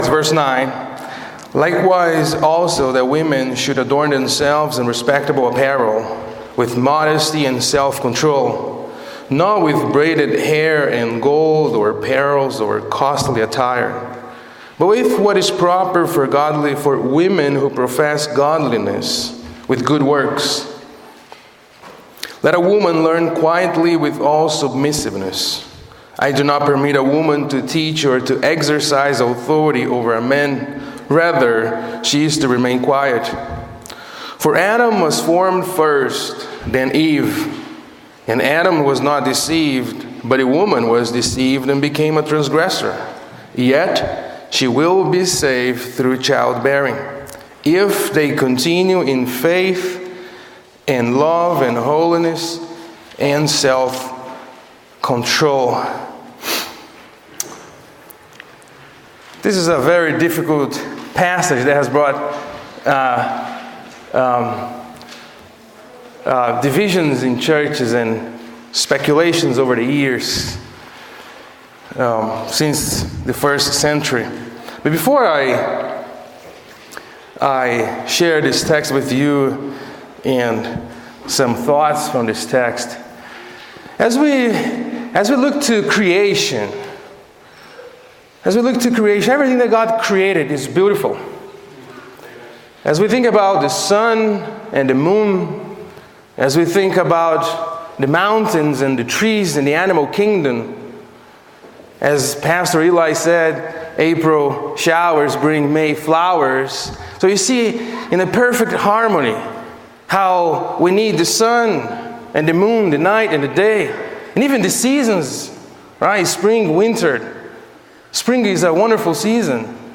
Women in the Church | SermonAudio Broadcaster is Live View the Live Stream Share this sermon Disabled by adblocker Copy URL Copied!